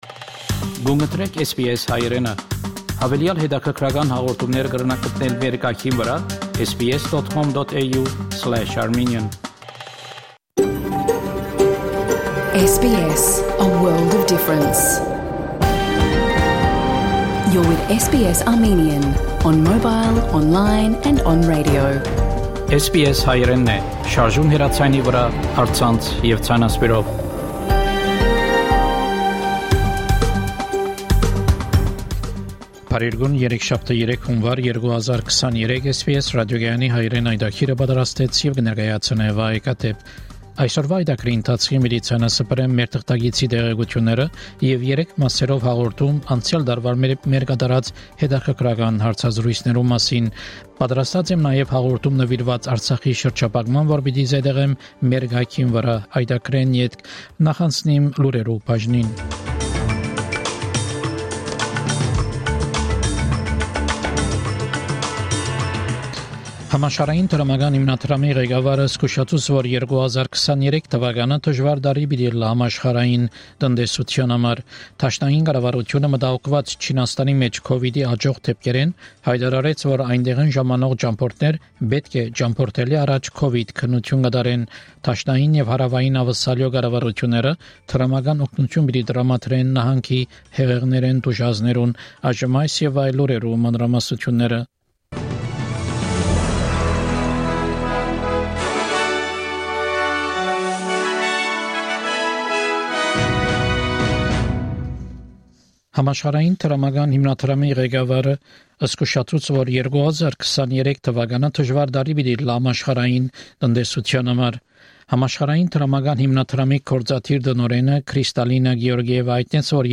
SBS Armenian news bulletin – 3 January 2023